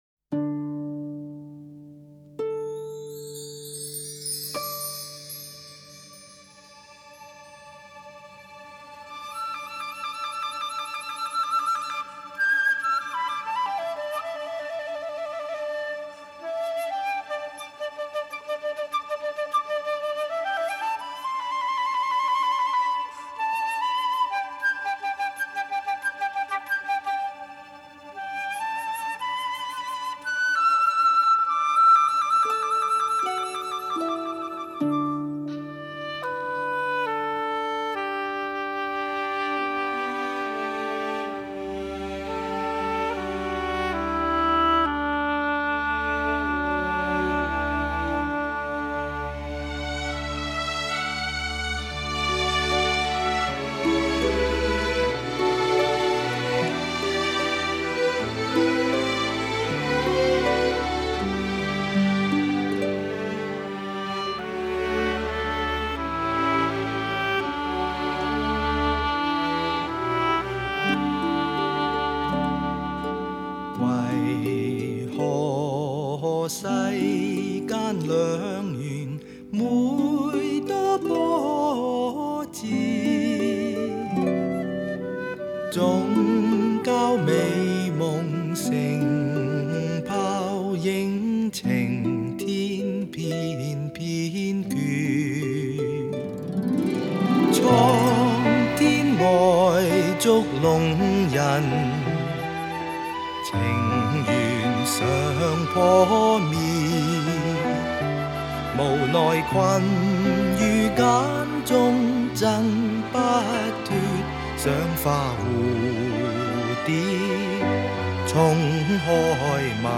Ps：在线试听为压缩音质节选，体验无损音质请下载完整版 Music…